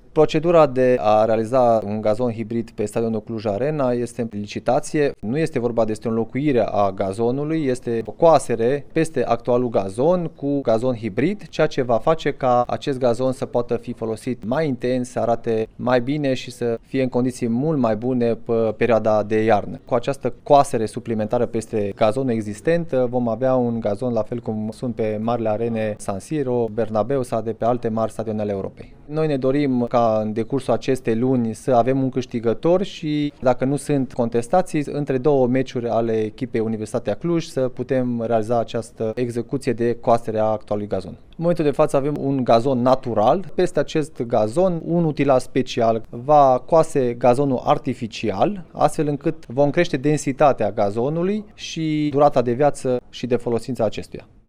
Radu Rațiu, vicepreședintele Consiliului Județean Cluj: